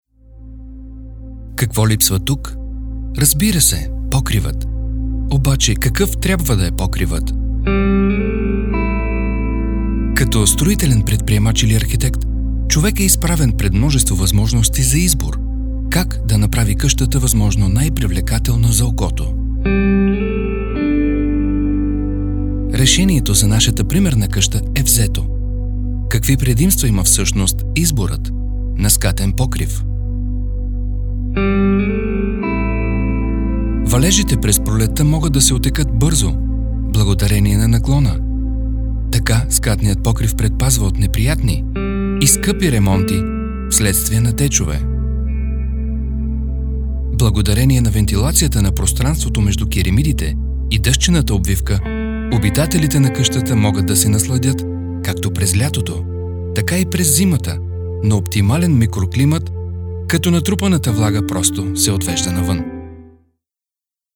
Native speaker Male 30-50 lat
Experienced voice actor with a deep and warm voice timbre.
Nagranie lektorskie